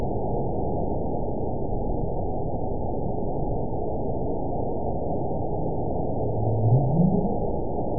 event 922766 date 03/29/25 time 07:39:47 GMT (2 months, 2 weeks ago) score 9.24 location TSS-AB04 detected by nrw target species NRW annotations +NRW Spectrogram: Frequency (kHz) vs. Time (s) audio not available .wav